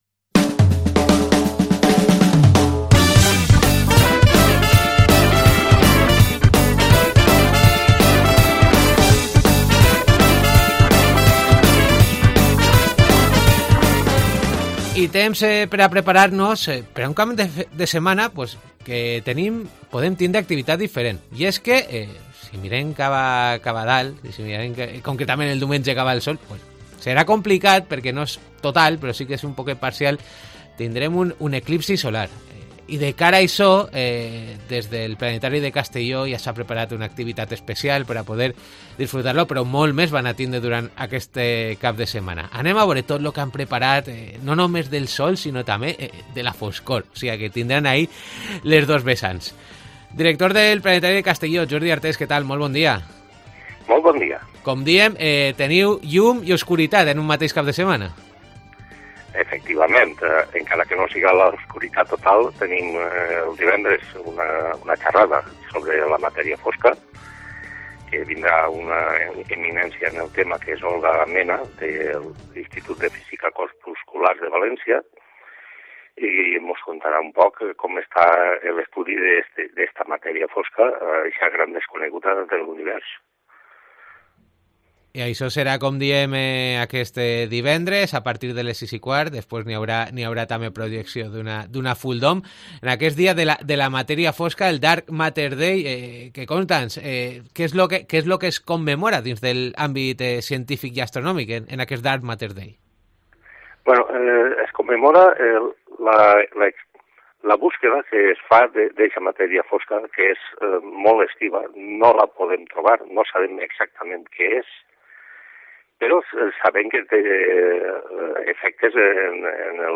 desde el Planetari de Castelló